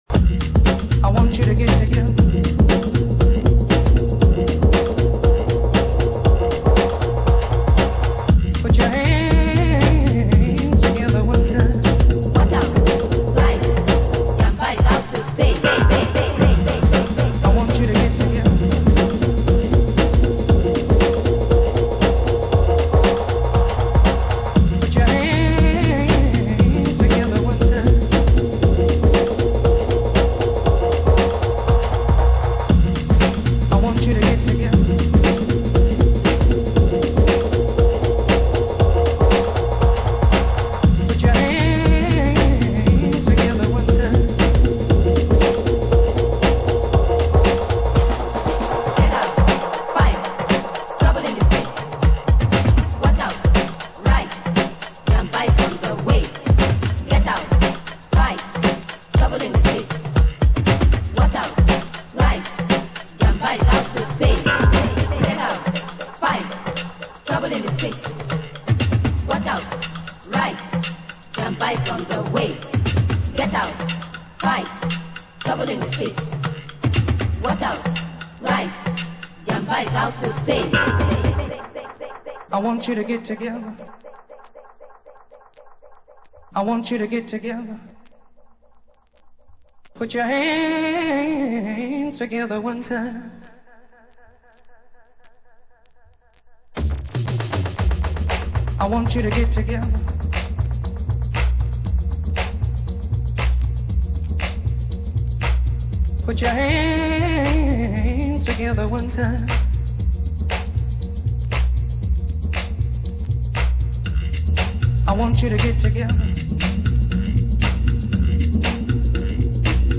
パーティー・チューン！！